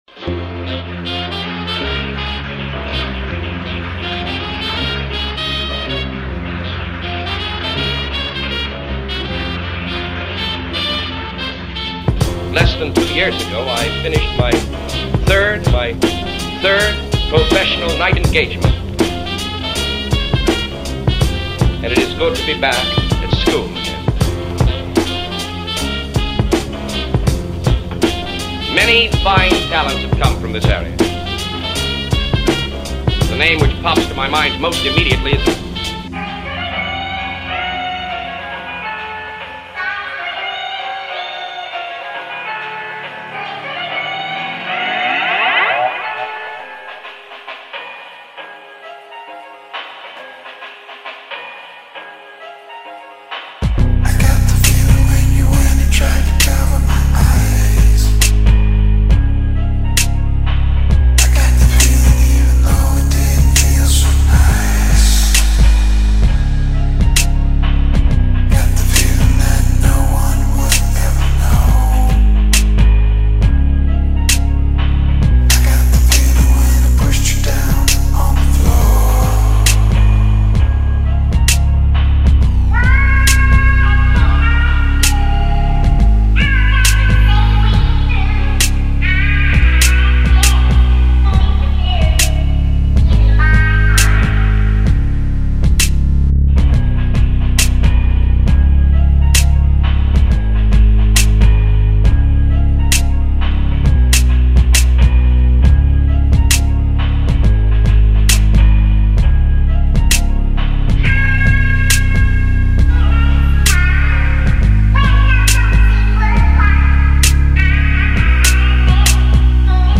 experimental beatmaker
a cohesive sixteen minute aural suite